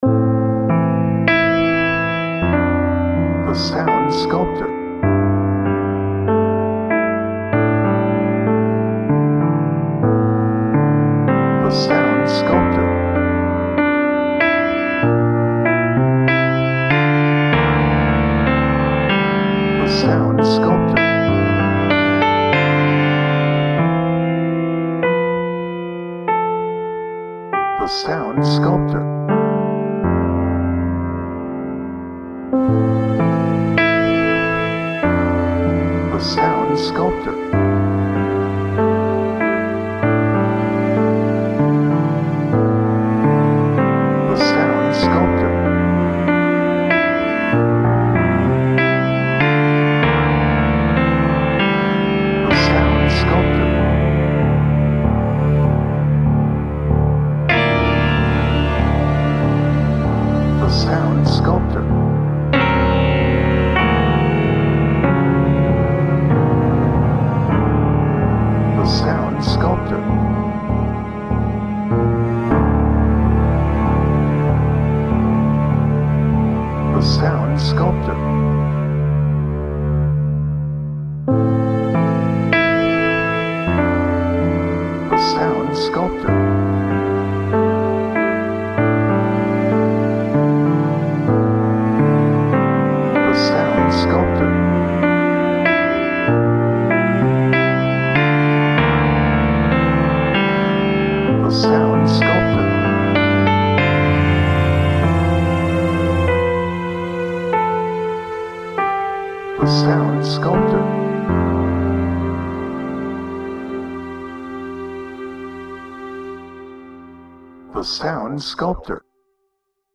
Introspective
Reflective
Sad